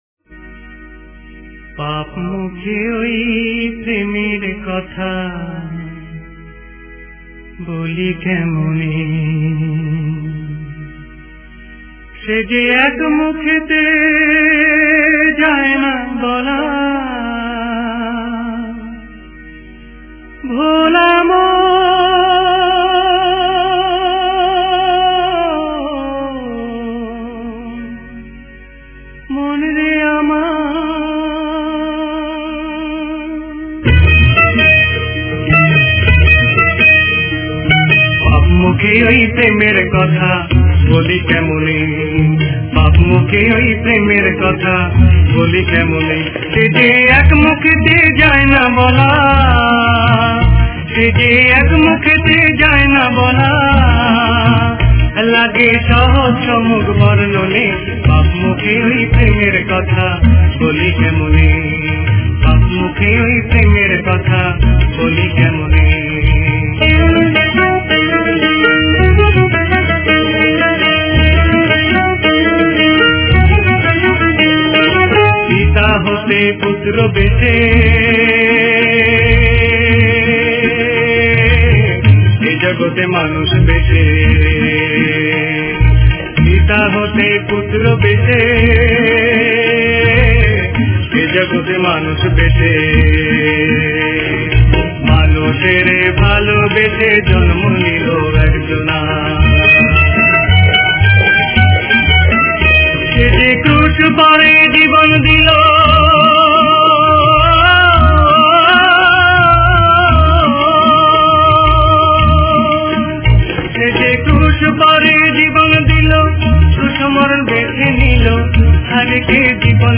Directory Listing of mp3files/Bengali/Devotional Hymns/Good Friday/ (Bengali Archive)